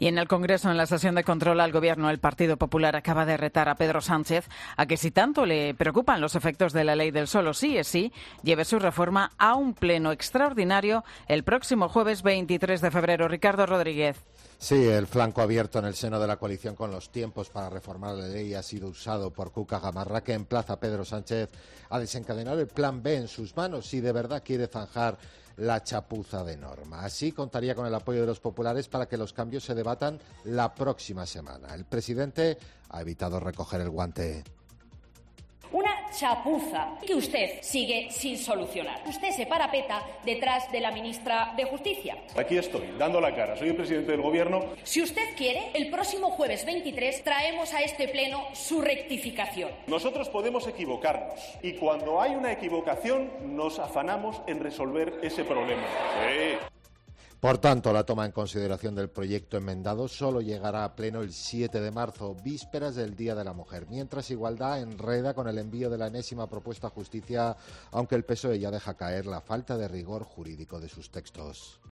Sesión de Control al Gobierno en el Congreso: Reto del PP a Sánchez sobre la ley del sí es sí